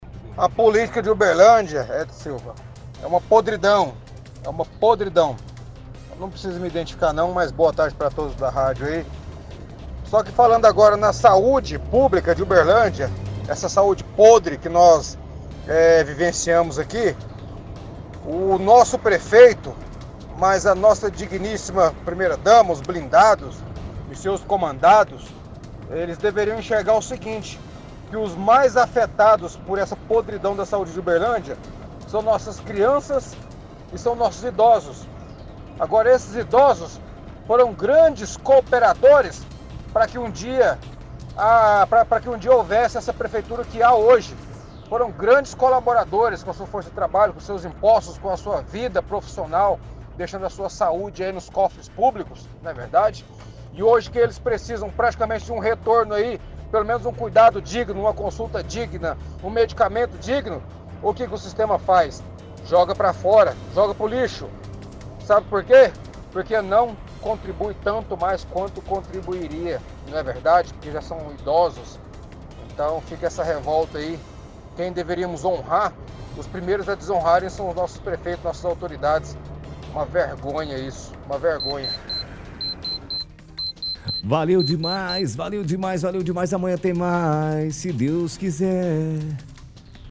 Mensagem dos ouvintes – Saúde
– Ouvinte reclama da saúde na cidade e diz que os mais afetados pela “podridão” são as crianças e idosos, que já contribuíram com muitos impostos.